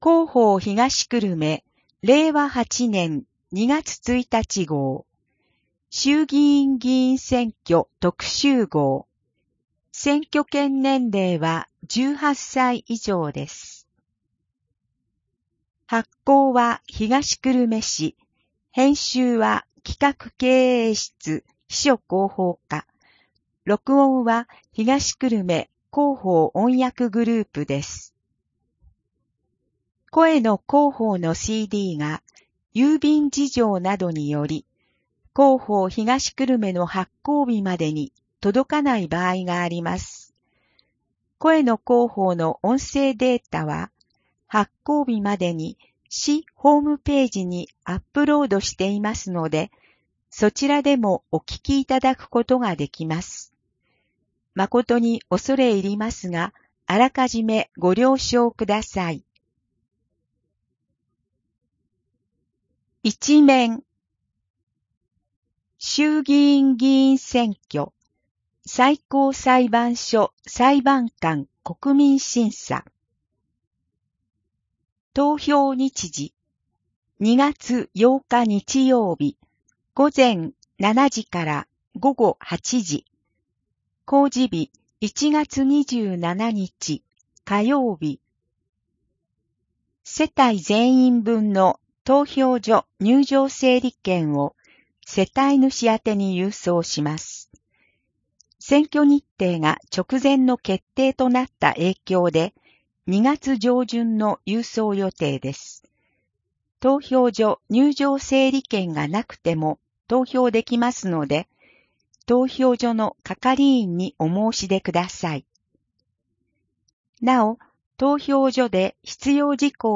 声の広報（令和8年2月1日号選挙特集号）